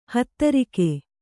♪ hadarikku